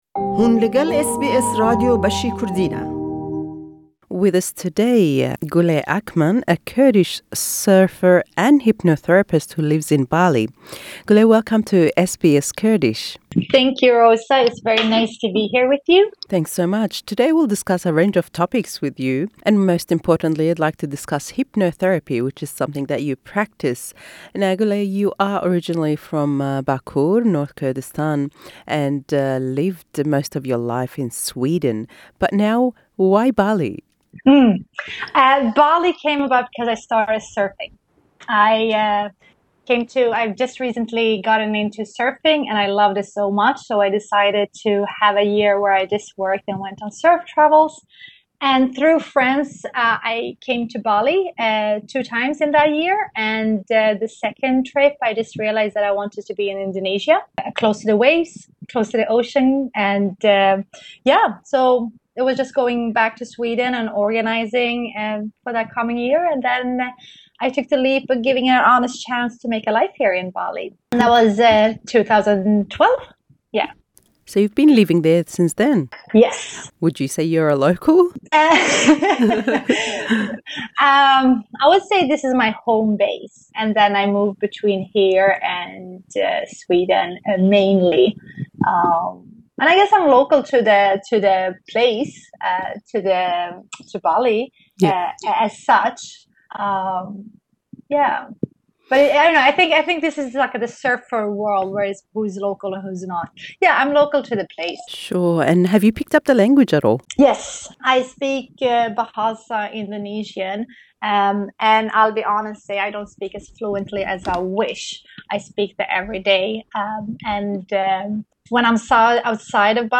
SBS Kurdish